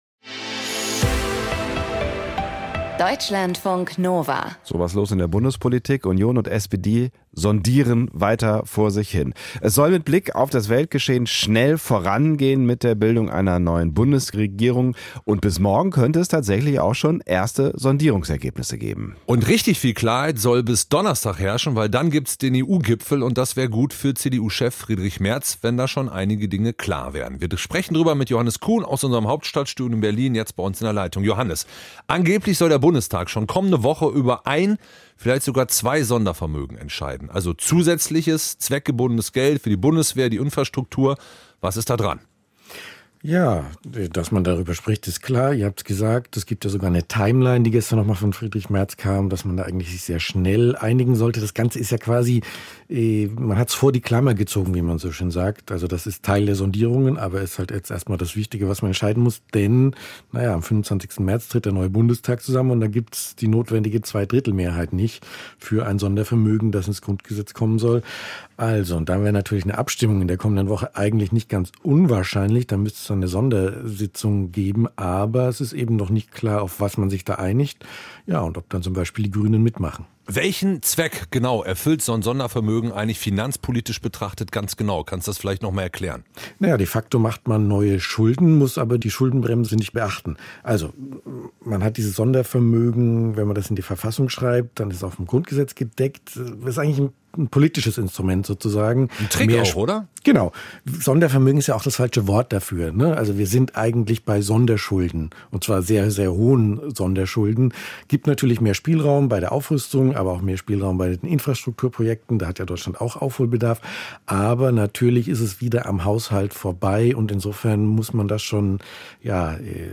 Meinung & Debatte Politik Kommentar zu Kulturkürzungen Kunst ist risikoreich – aber fundamental 04:36 Minuten Kultur als eine Art Autoradio: nice to have, aber das Auto fährt auch ohne?